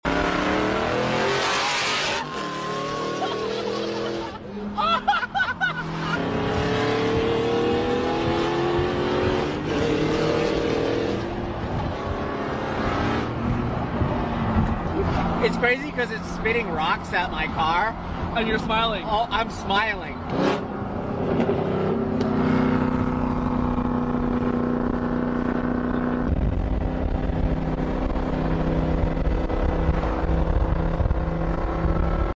Volume up !! 🏎 If you love engine sounds then you are on right place.